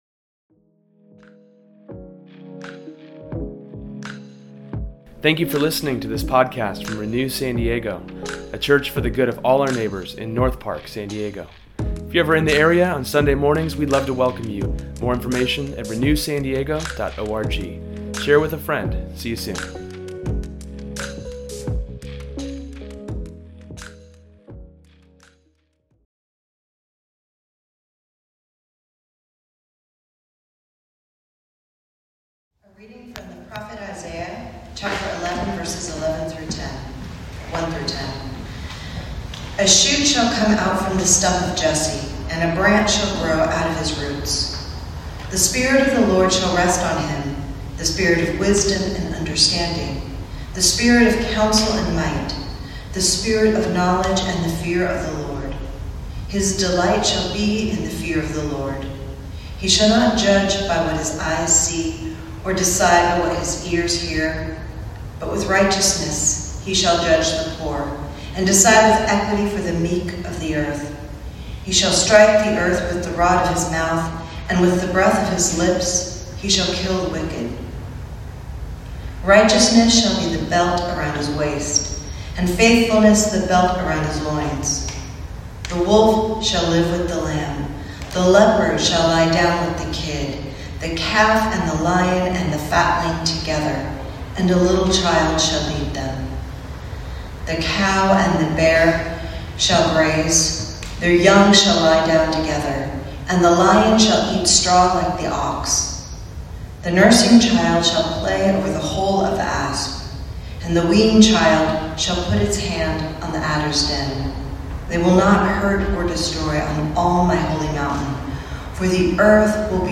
Today’s sermon explores how Jesus understands the poor and the powerless. The Son of God didn’t come down as someone with large amounts of wealth and power, he came into a family who was considered poor.